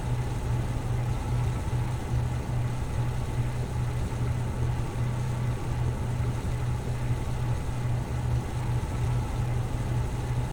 fan_idle.ogg